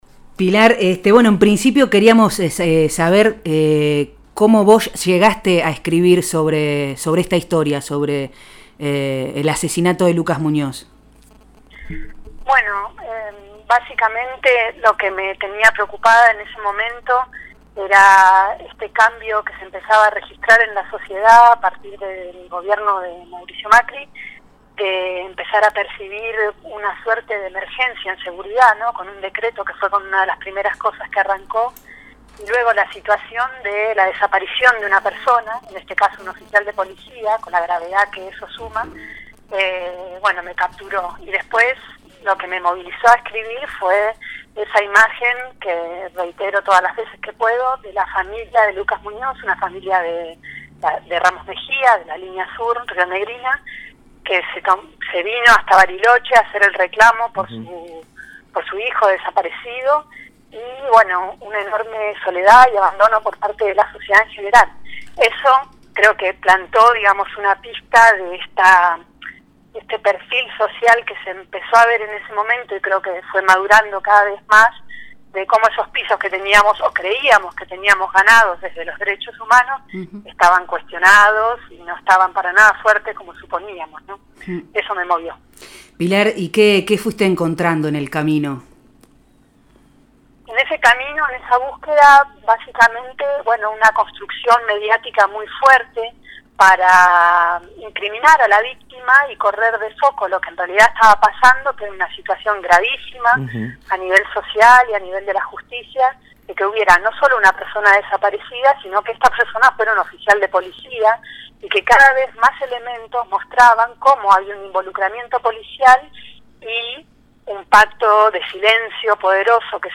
En diálogo con Hacete los rulos habló sobre qué la llevó a escribir sobre la vida, desaparición y muerte del joven policía.